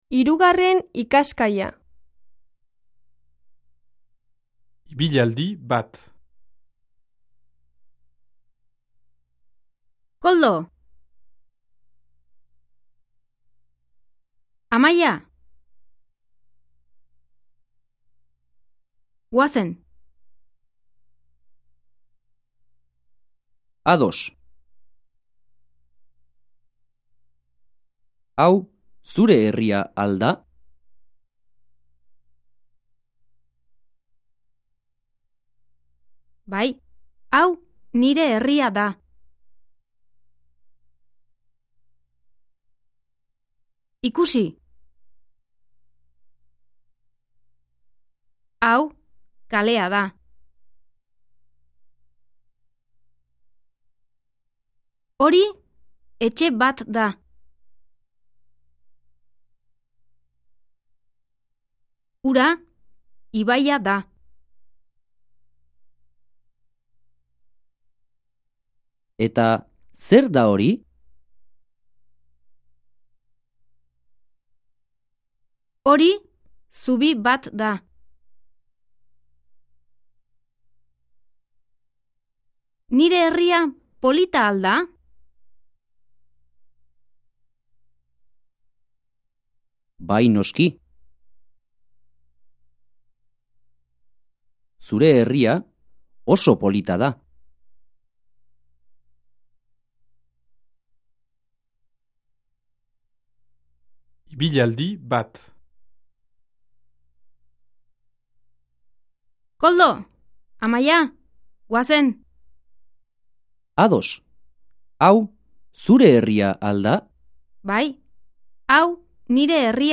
Диалог
AHOSKERA Правильное произношение
1 koldo! amaya! goassen! 2 ados. au ssure erria al da? 3 bay au nire erria da. ikusi! 5 ori eche bat da 6 ura ibaya da